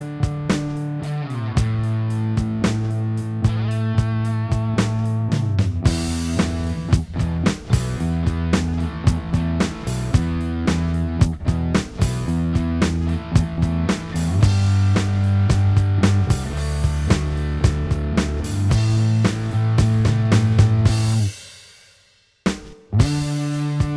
Minus Acoustics Soft Rock 3:08 Buy £1.50